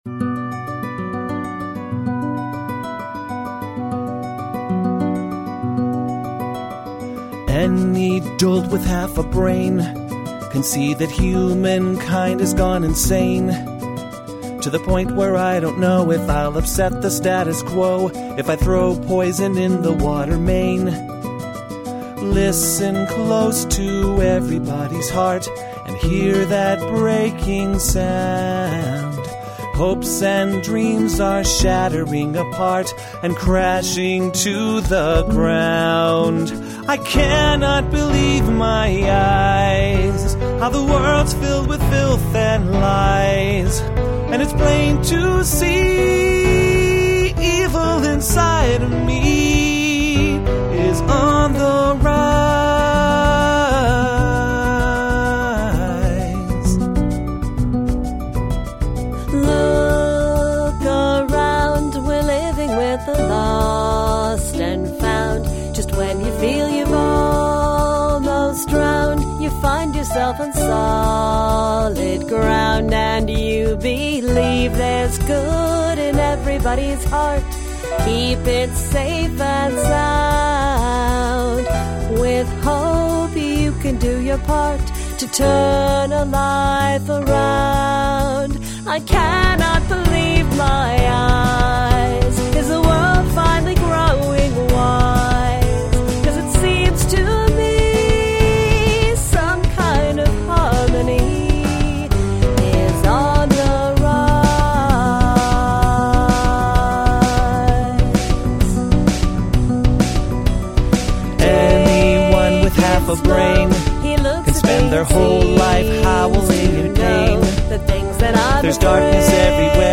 I used Reaper recording software on my computer.